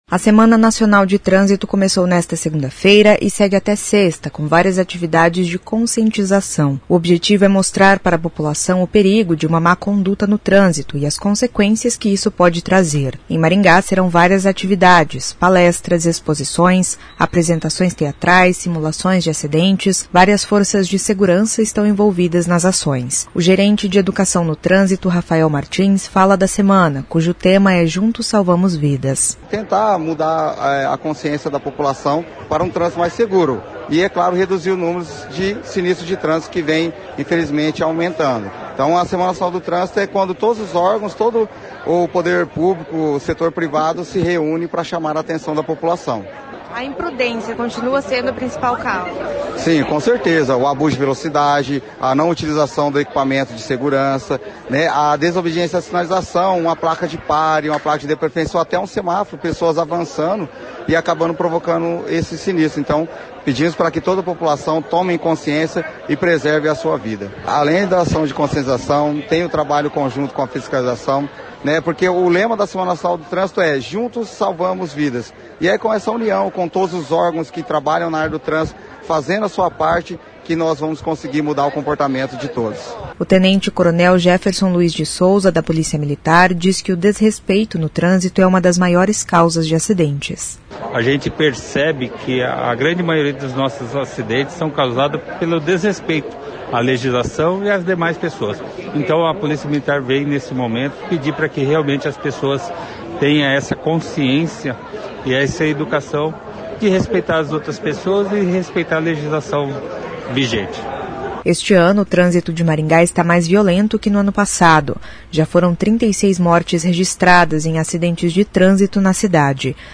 O secretário de Mobilidade Urbana, Gilberto Purpur, diz que a semana é um marco para as ações cotidianas de atividades e fiscalização.